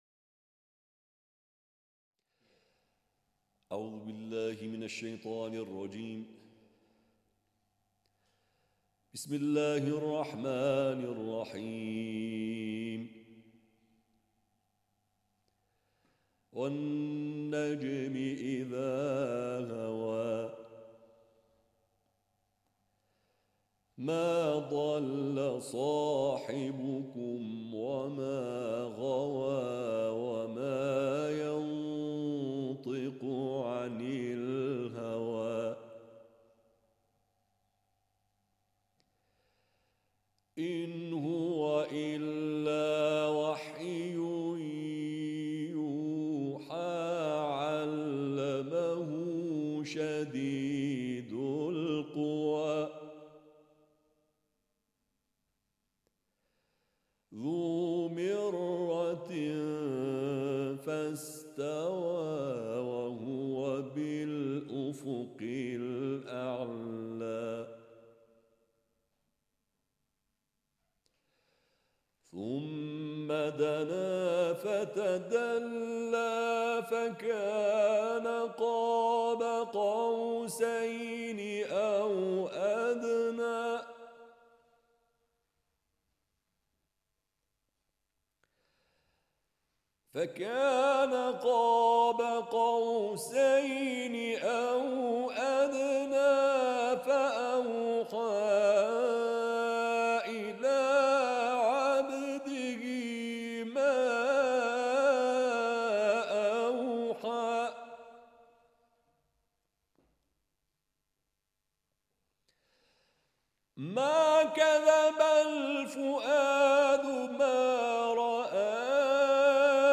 فایل صوتی تلاوت آیات ۱ تا ۱۸ سوره نجم